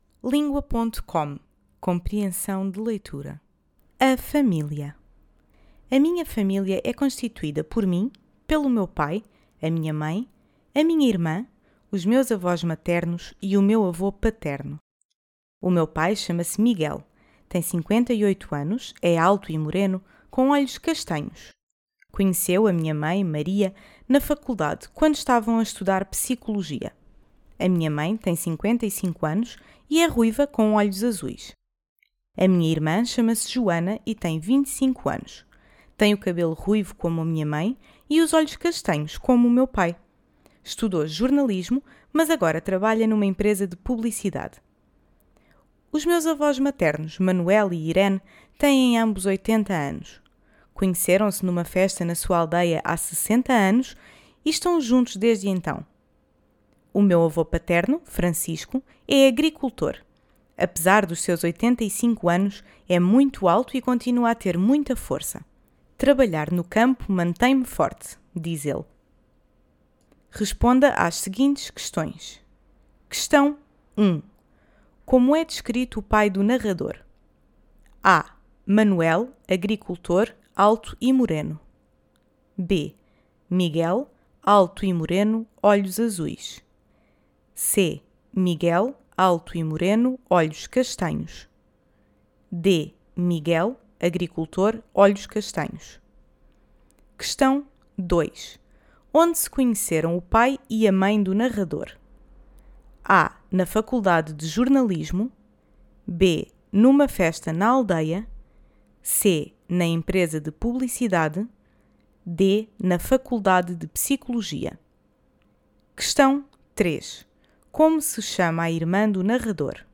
Portugal